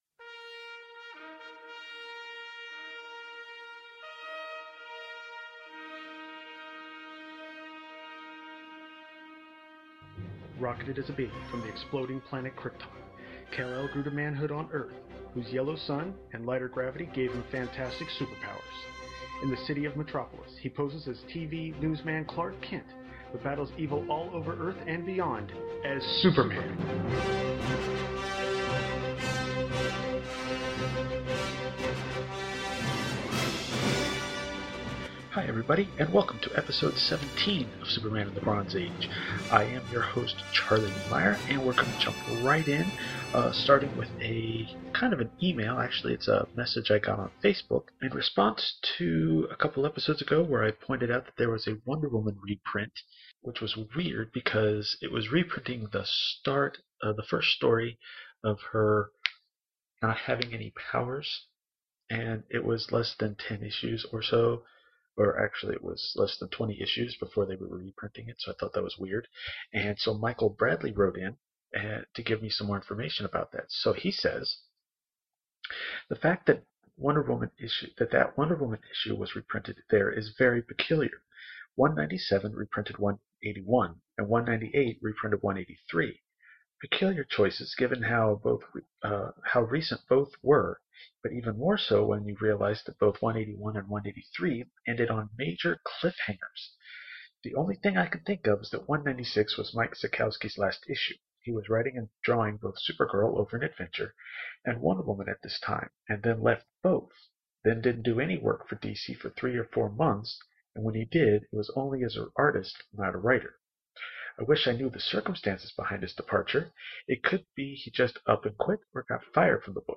Also, I’d like to apologize for the quality of the episode. I was not recording in the best of conditions.